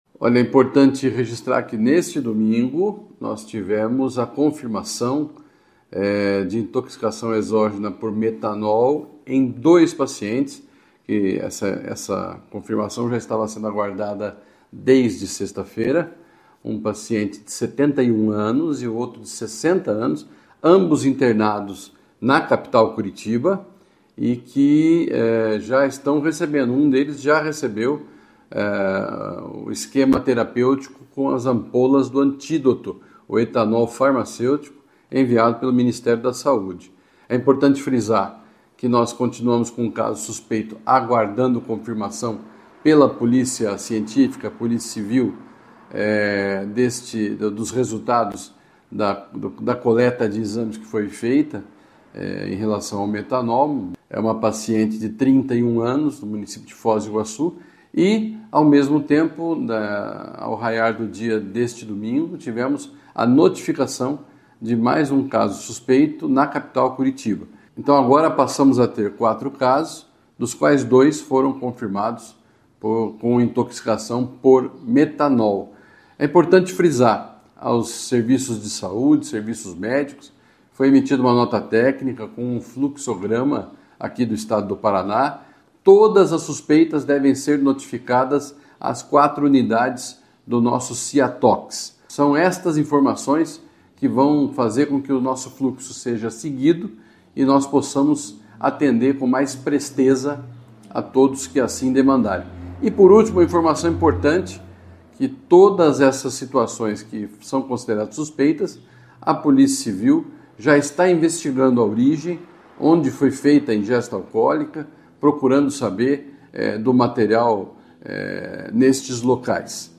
Ouça o que diz o secretário de Saúde do Paraná Beto Preto: